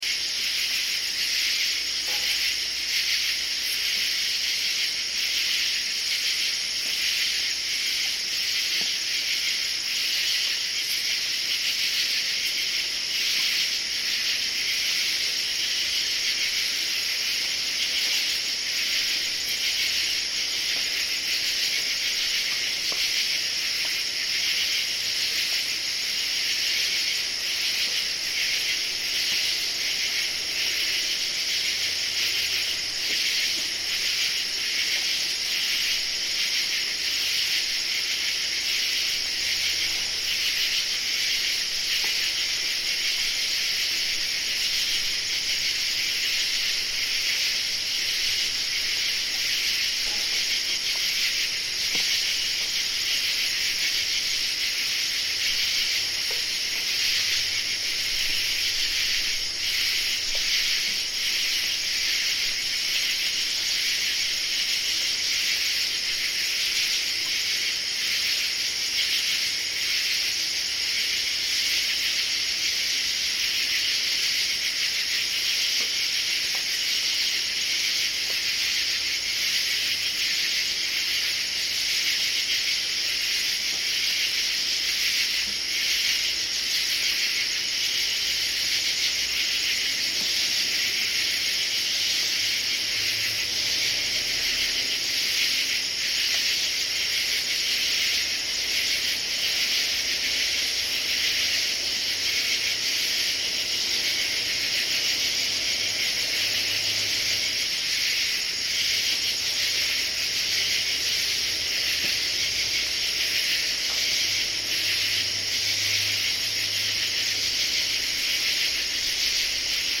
Cicadas and katydids singing
Cicadas and katydids singing in Dingmans Ferry, Pennsylvania, 9 Aug 2013. This is a recording of the intense sounds of cicadas and katydids singing in the deep night.
The recording was made using two condenser microphones in stereo array through a Mackie 12 track board directly to digital.